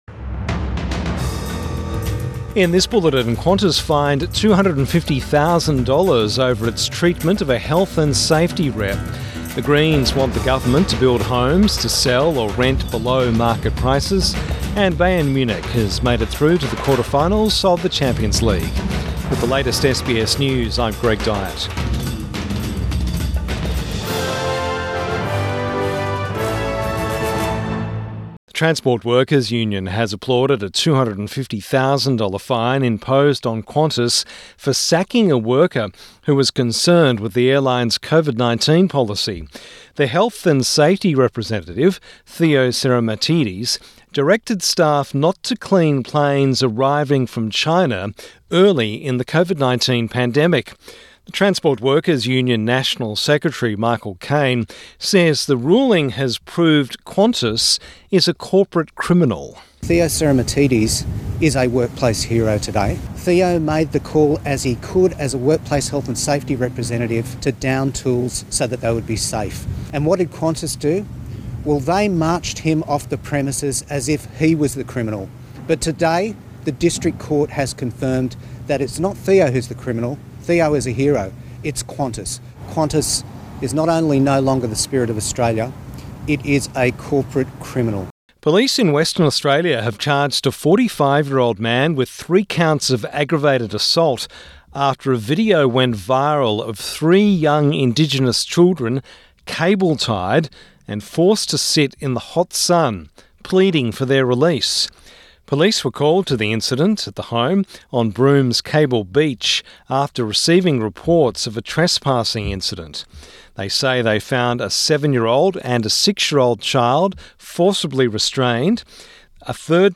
Evening News Bulletin 6 March 2024